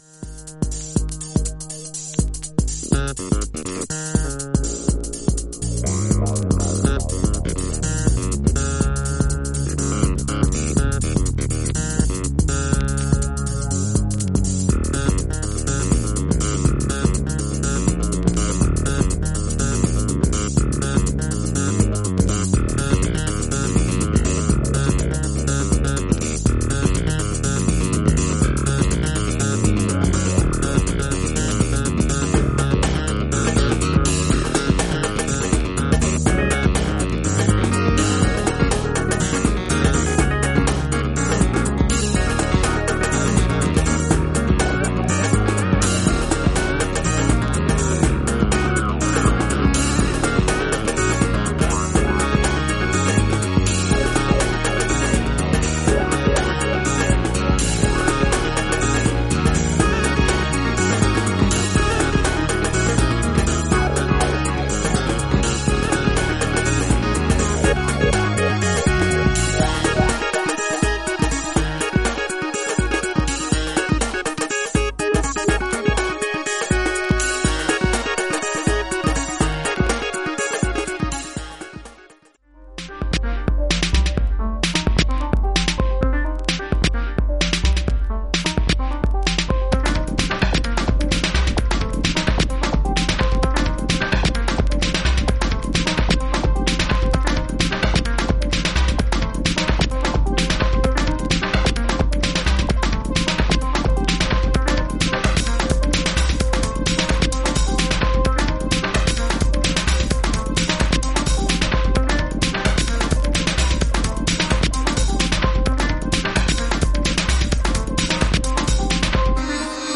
クラビやシンセのメロディが入れ替わりリードを取るファンキー・チューン
エレクトリックかつイルなシンセの上音がクールに響くマシン・ファンク・ナンバー